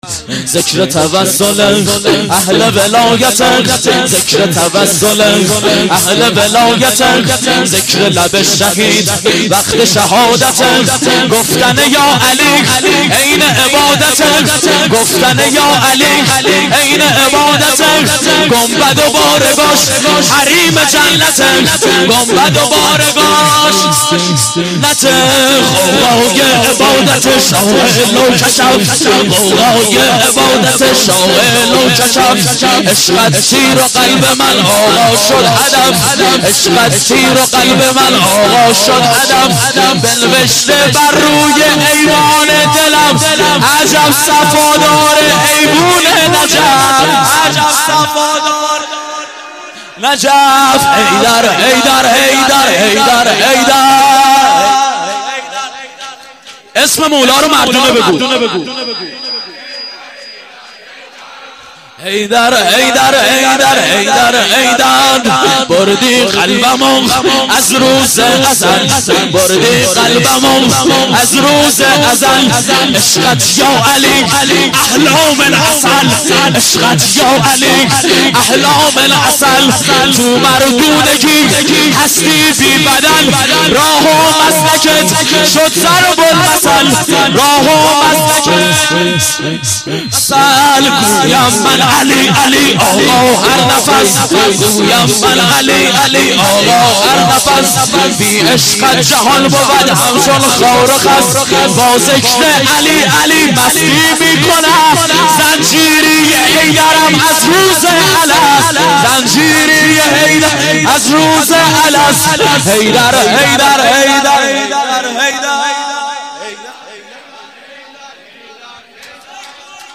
شور - ذکر توسله اهل ولایته